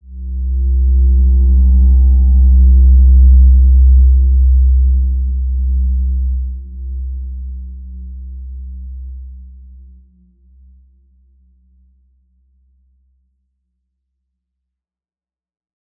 Evolution-C2-mf.wav